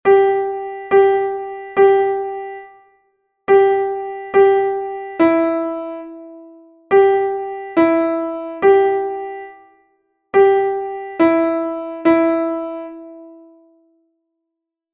Melodic reading practice
Exercise 1 G and E